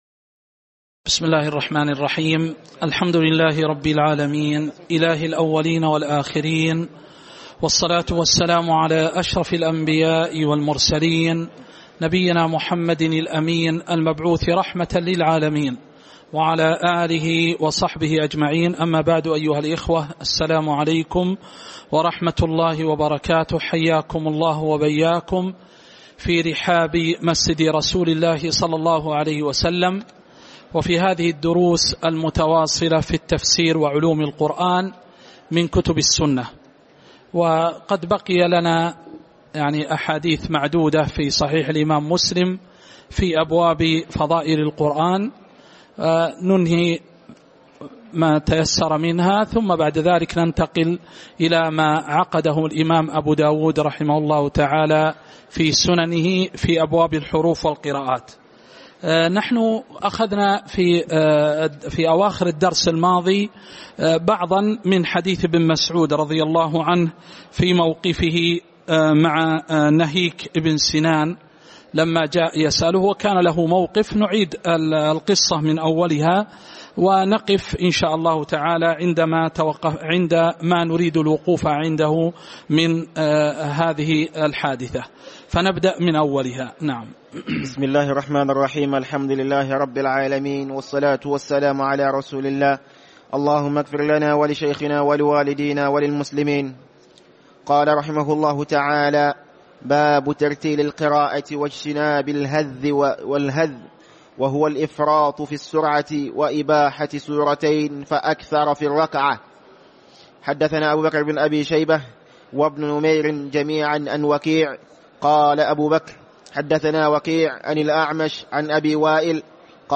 تاريخ النشر ٥ رجب ١٤٤١ هـ المكان: المسجد النبوي الشيخ